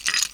1 channel
CoinSpawn.mp3